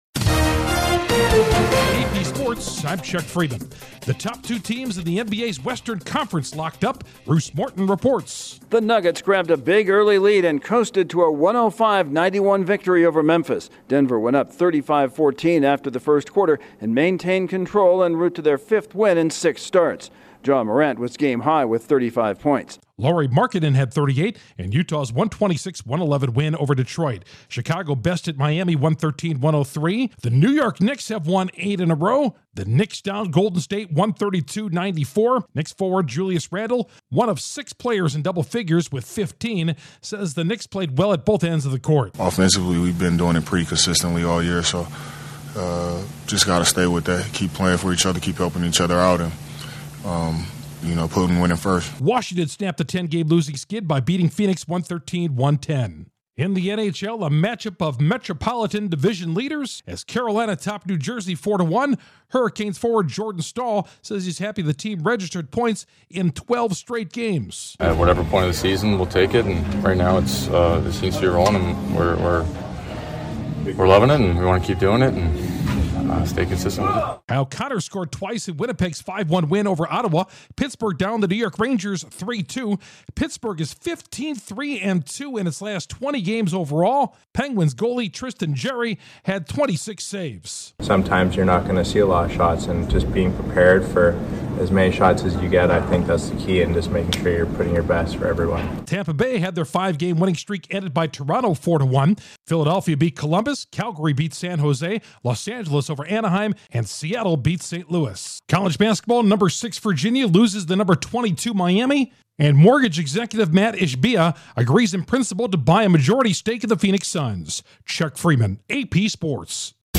AP Sports SummaryBrief at 10:02 p.m. EST
The top two teams in the NBA's Western Conference battle, Knicks continue to win, the NHL's Metropolitan leaders meet and the Suns are sold. Correspondent